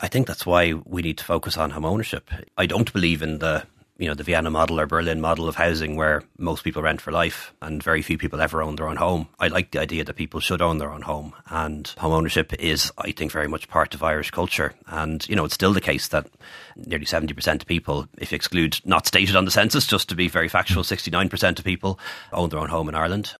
Taoiseach Leo Varadkar says he’s not a believer in long term rental: